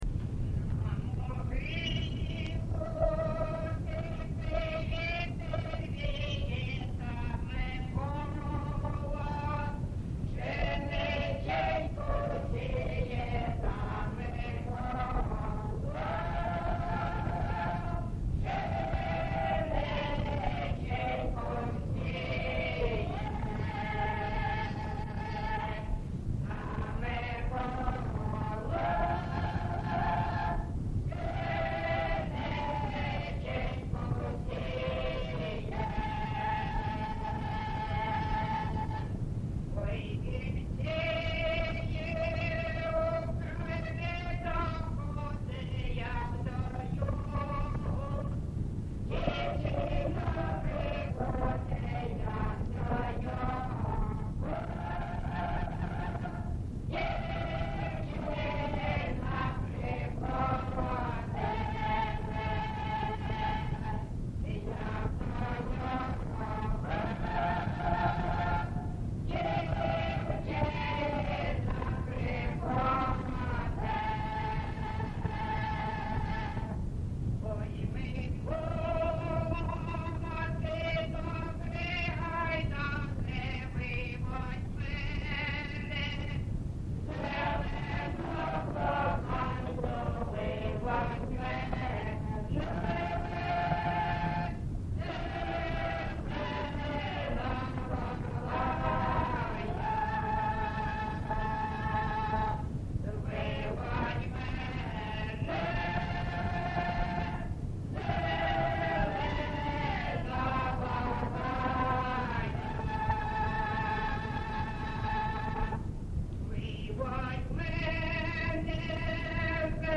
ЖанрПісні з особистого та родинного життя
Місце записус. Семенівка, Краматорський район, Донецька обл., Україна, Слобожанщина